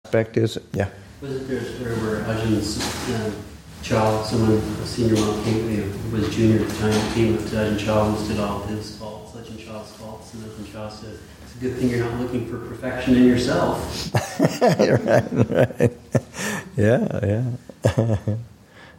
Story